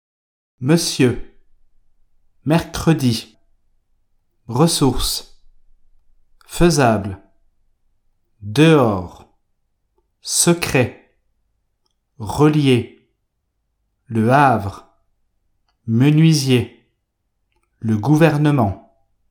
Le son [ə] est appelé « schwa » ou « e muet » (aussi dit « caduc » ou « instable »).
Le son /ə/